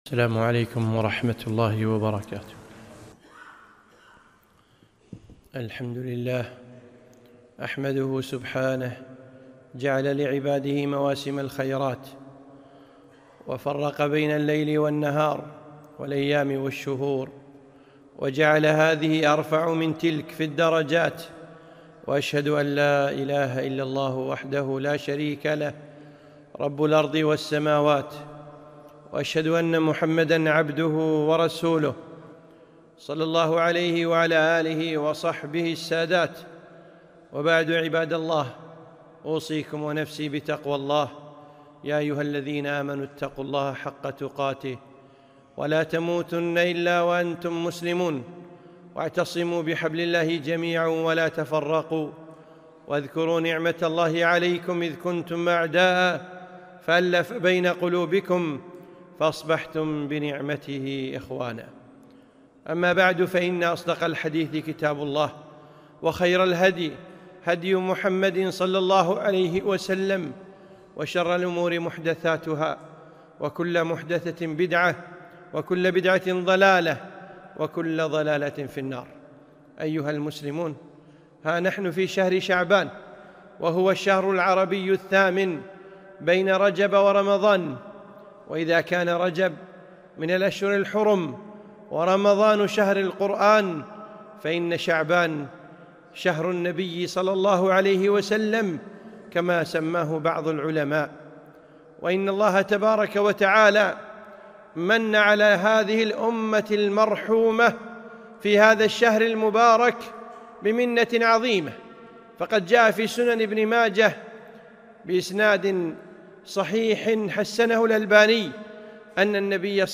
خطبة - فضائل شعبان